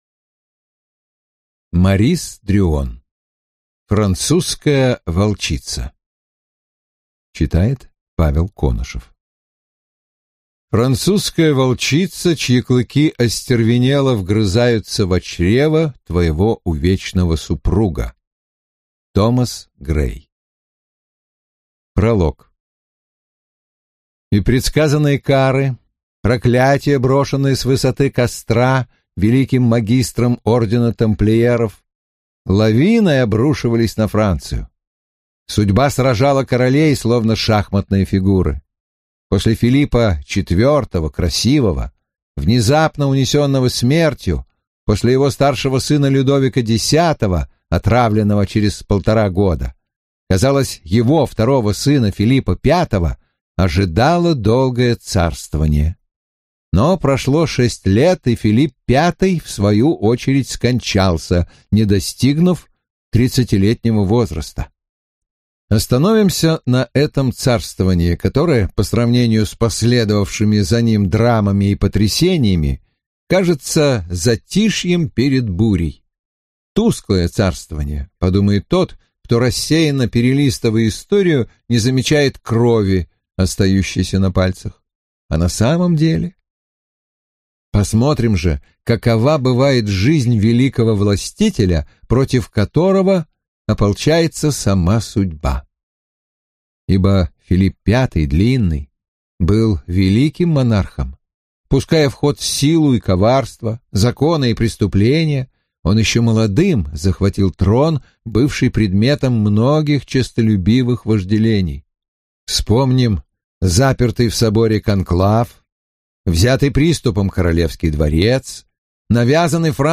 Аудиокнига Французская волчица - купить, скачать и слушать онлайн | КнигоПоиск